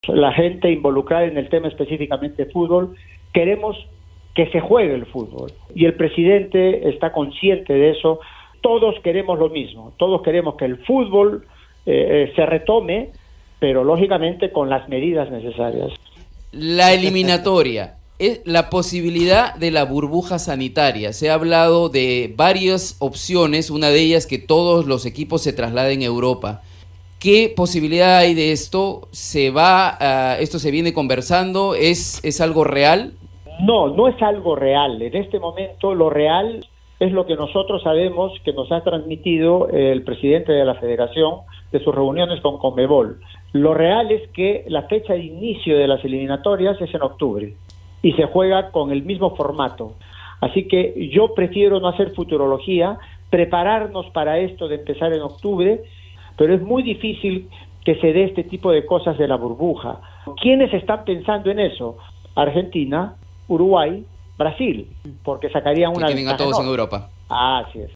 Juan Carlos Oblitas, director deportivo de la Federación Peruana de Fútbol, en Radio Programas del Perú
«La imagen que hemos dado al mundo es una imagen terrible. Todo tiene que partir de asumir responsabilidades», comentó Oblitas en diálogo con Radio Programas del Perú.